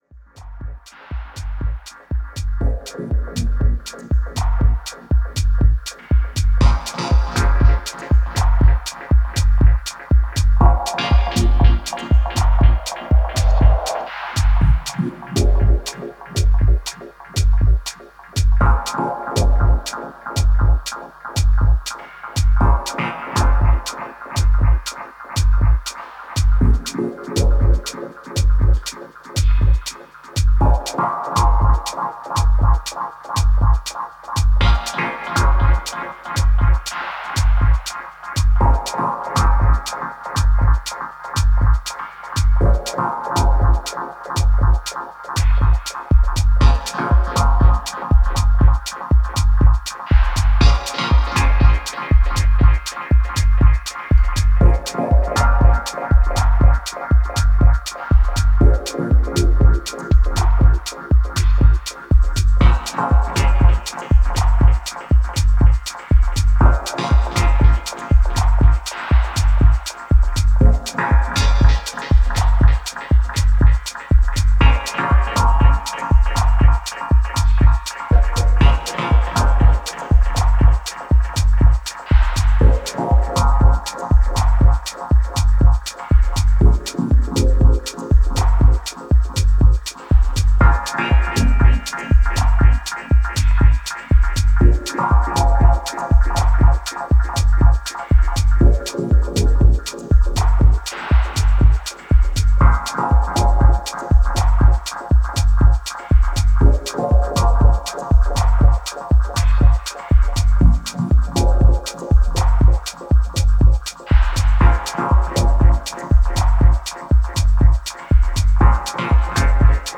heavy dub techno jams
Techno Dub